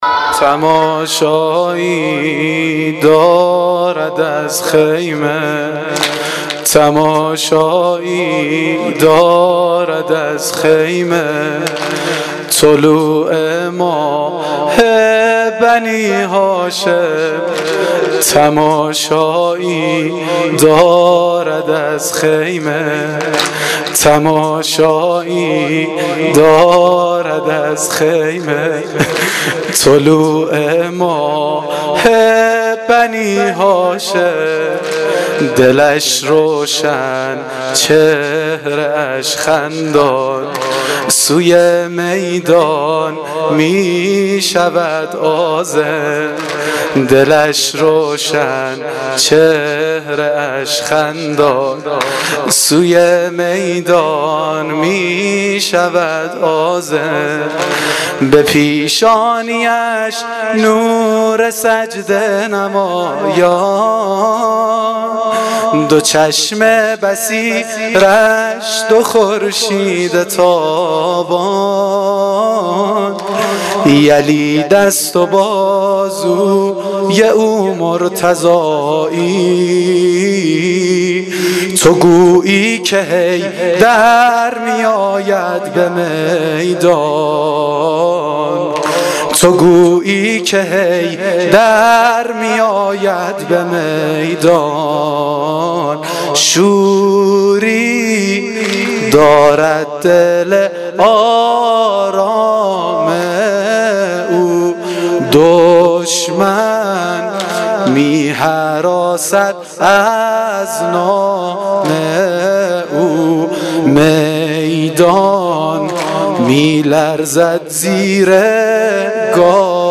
واحد سنگین شب نهم محرم